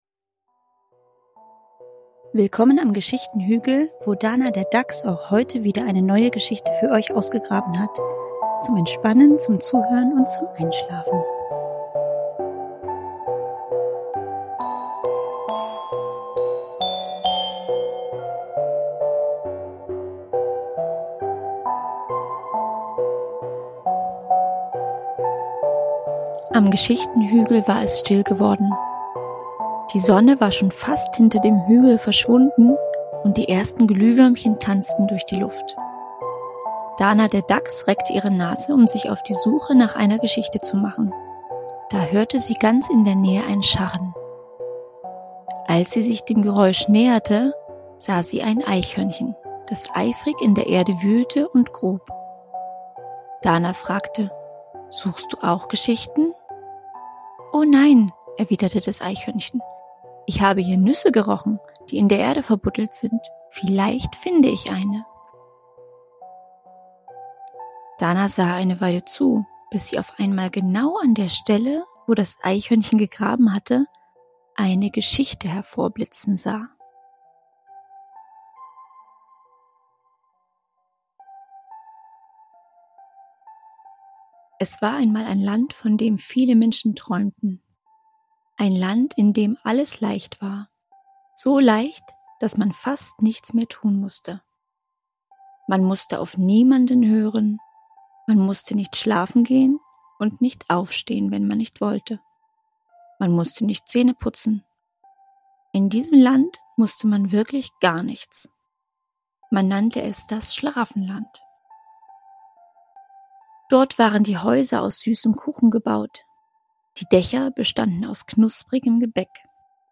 Geschichten für Kinder vom Geschichtenhügl
Ruhige Geschichten für Kinder – zum Entspannen, Zuhören und Einschlafen.
neuen, sanft erzählten Geschichte – ohne Werbeunterbrechung und